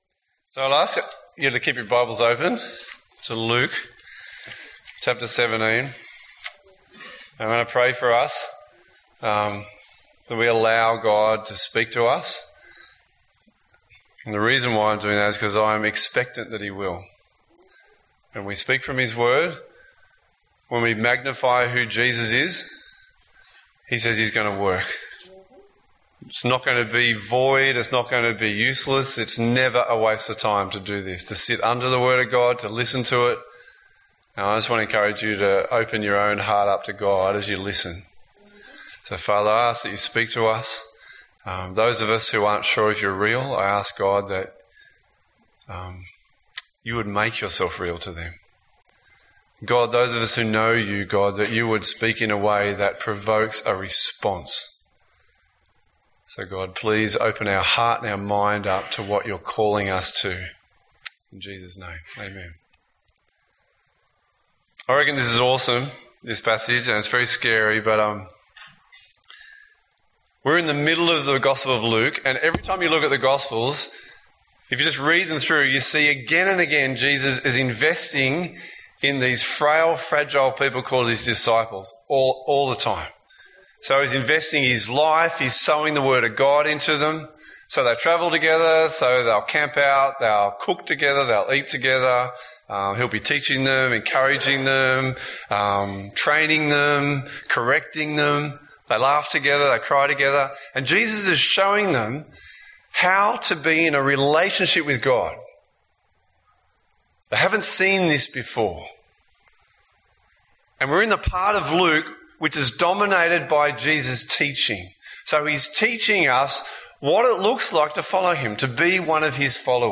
Sermons - AM - CCBC